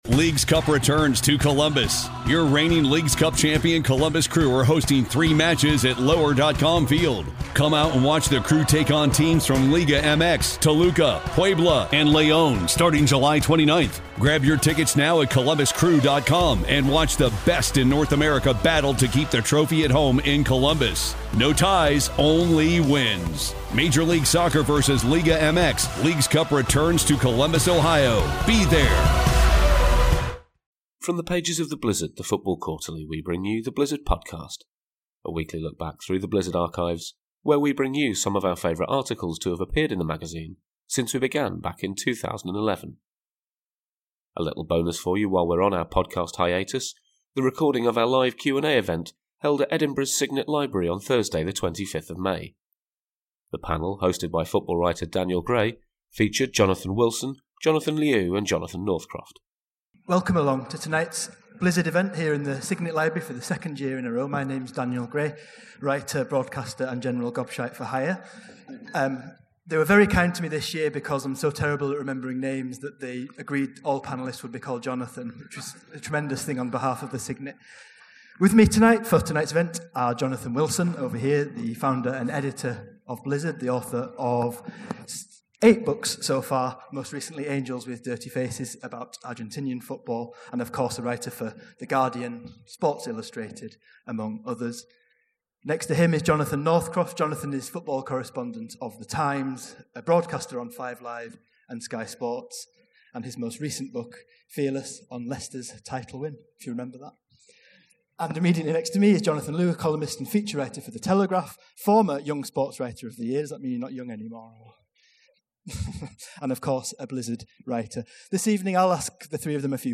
Despite being on a podcast hiatus while we work on some exciting things behind the scenes we still found time to do a live recording of our Q&A event in Edinburgh last week. This is the first half of that discussion.